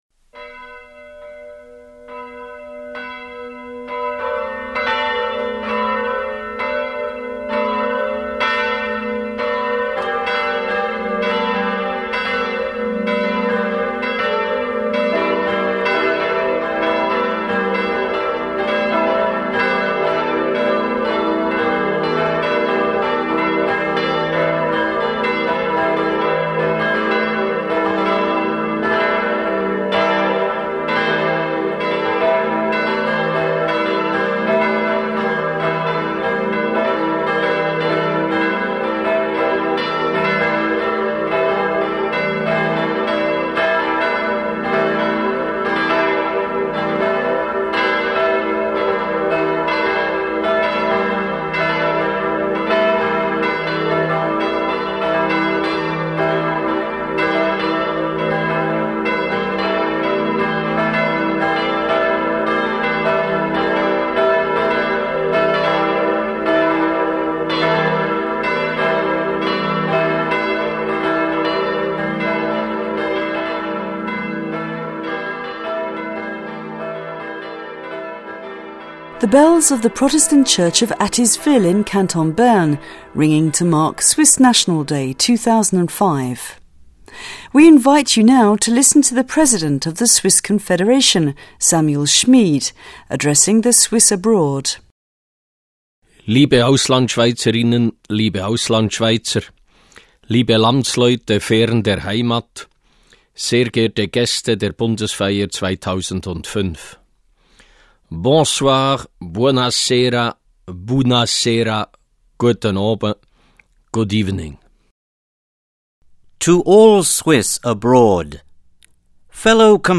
President Samuel Schmid's speech to the Swiss Abroad for Swiss National Day.